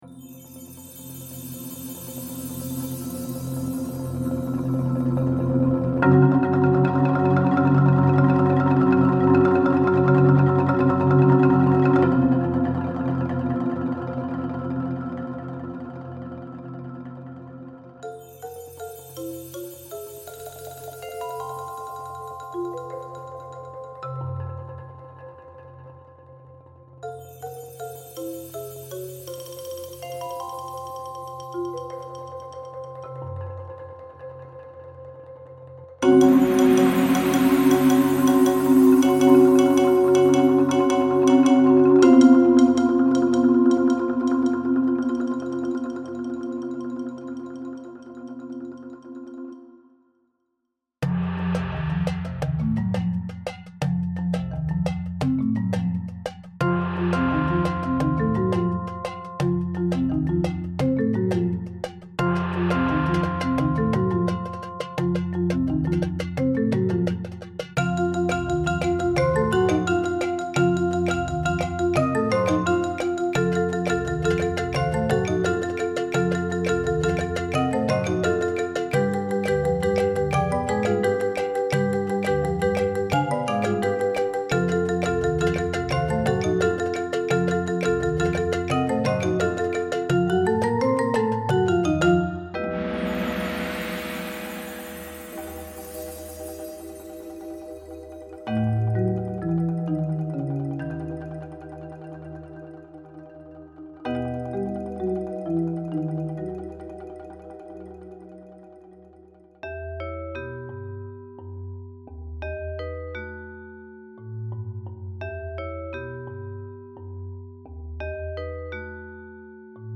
Dezett für Percussion-Ensemble
Oriental Scenes for Percussion-Ensemble